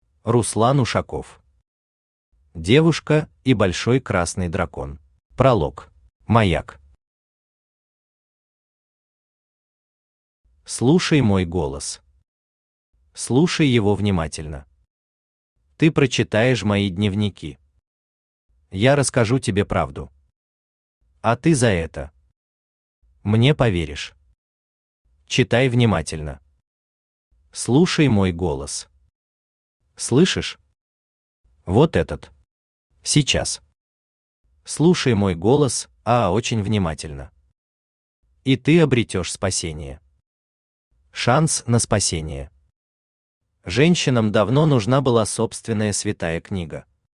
Аудиокнига Девушка и Большой Красный Дракон | Библиотека аудиокниг
Aудиокнига Девушка и Большой Красный Дракон Автор Руслан Ушаков Читает аудиокнигу Авточтец ЛитРес.